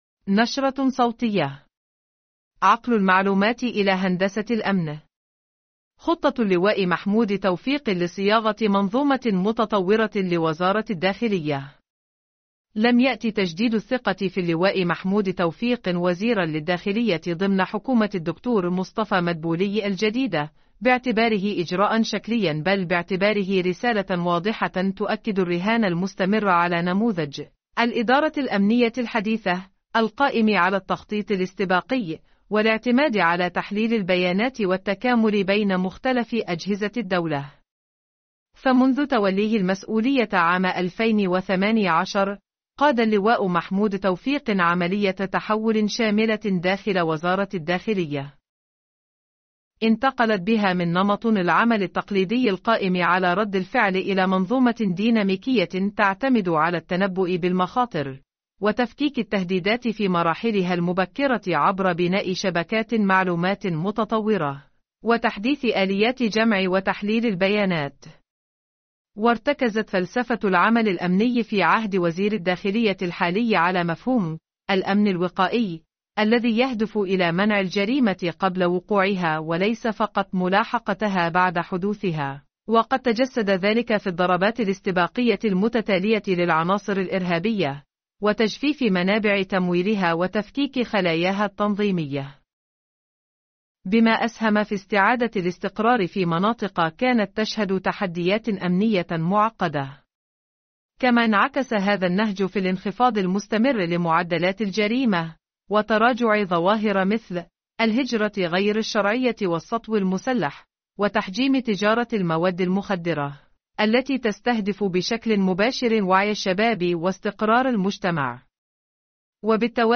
نشرة صوتية.. عقل المعلومات إلى هندسة الأمن.. خطة اللواء محمود توفيق لصياغة منظومة متطورة لوزارة الداخلية